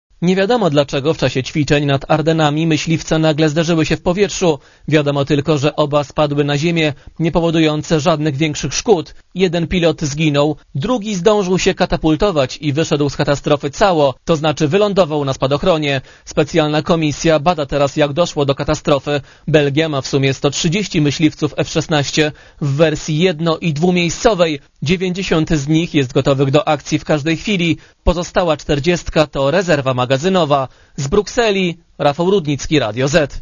Korespondencja z Brukseli (132Kb)